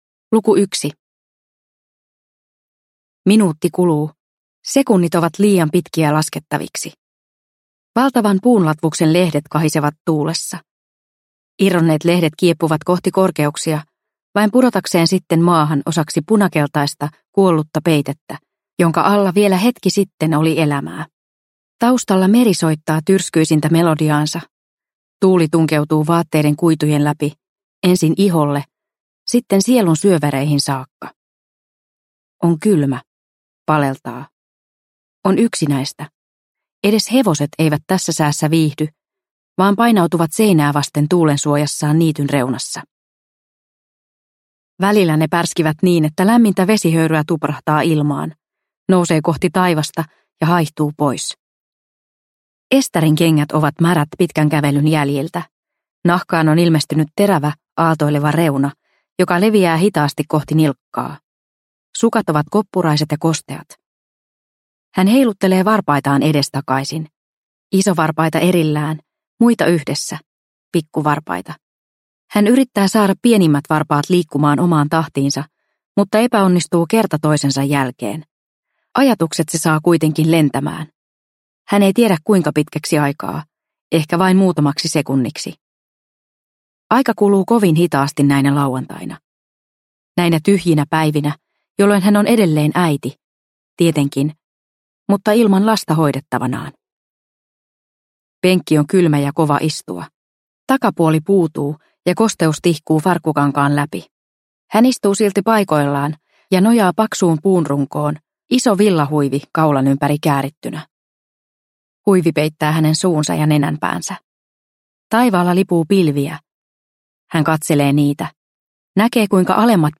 Tapaa minut tammen alla – Ljudbok – Laddas ner